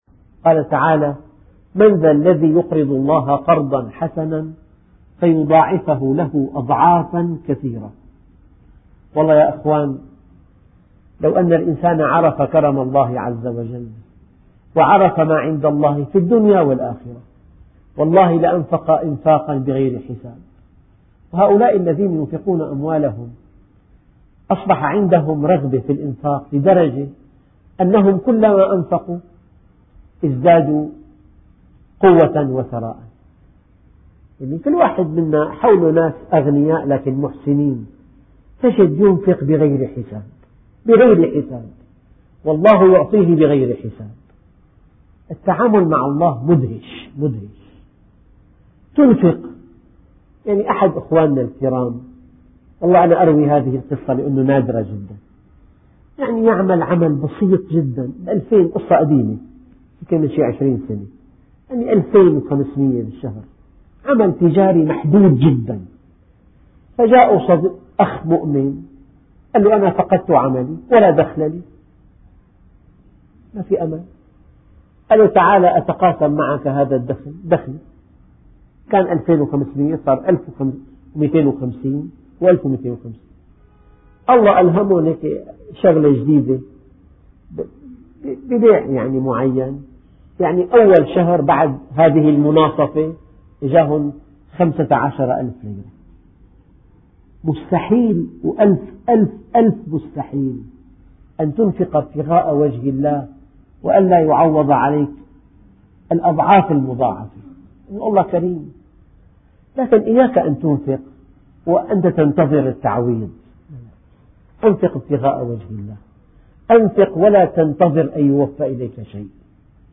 ماهي فوائد الصدقة ؟؟؟؟ ....... قصة مؤثّرة ........